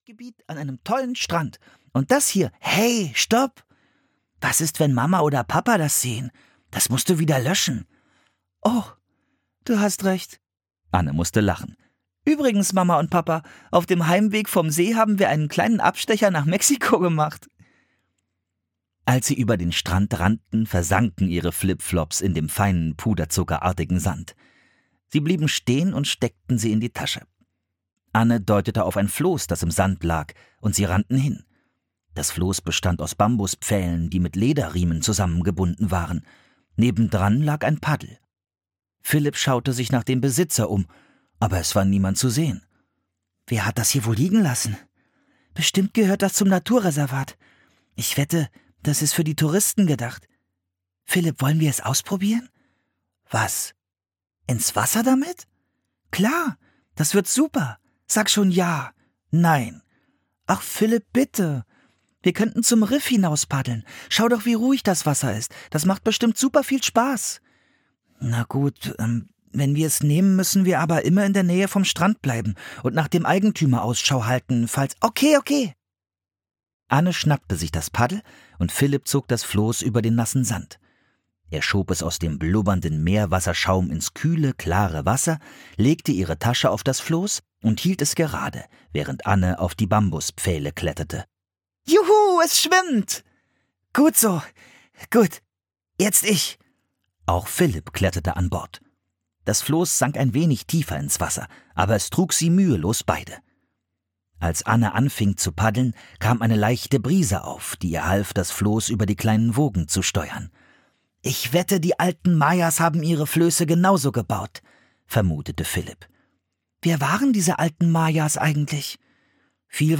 Der König der Mayas (Das magische Baumhaus 51) - Mary Pope Osborne - Hörbuch